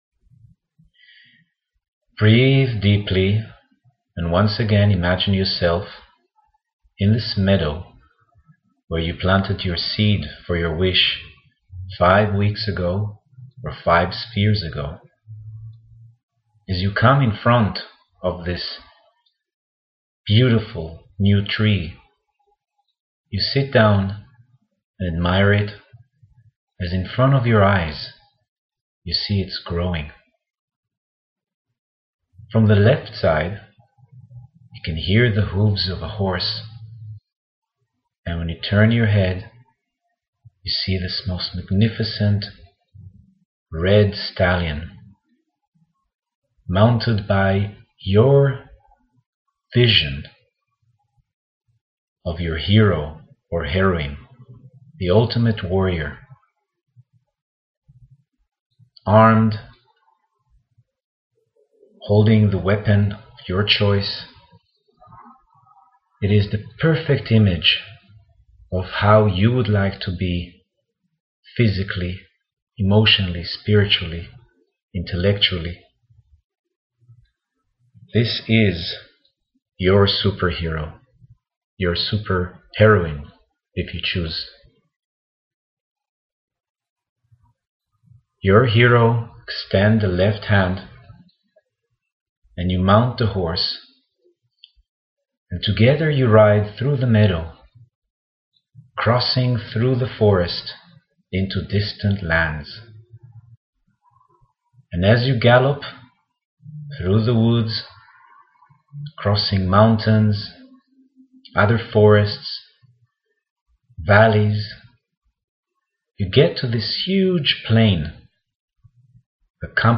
Listen to the Meditation on Severity. This meditation is designed to help you connect to the energy of the fifth sphere.